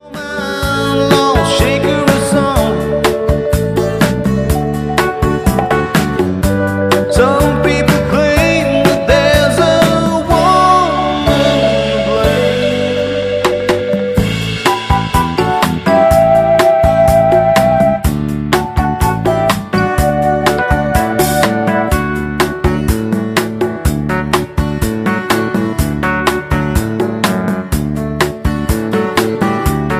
Backing track files: Country (2471)
Buy With Backing Vocals.